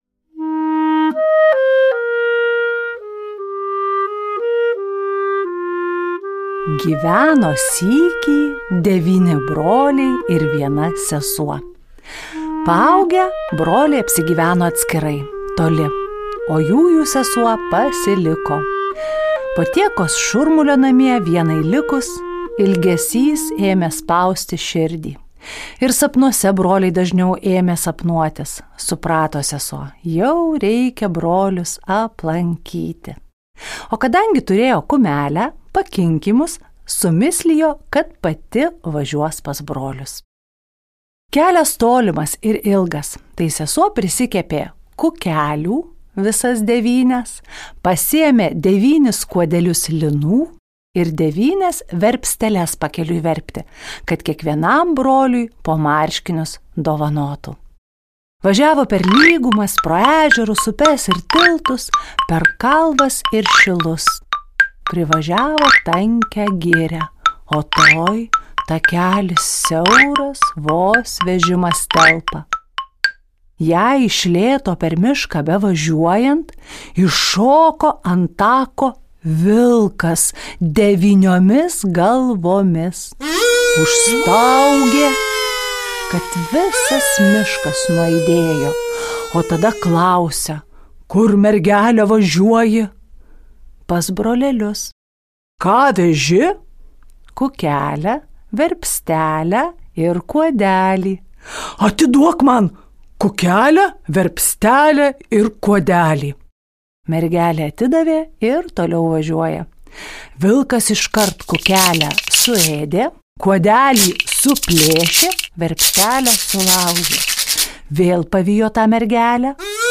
Ji šiandien grojo kanklėmis, kazoo, ksilofonu, kokiriko ir smulkiais perkusiniais instrumentais
Tinklalaidė įrašyta Lietuvos nacionalinės Martyno Mažvydo bibliotekos garso įrašų studijoje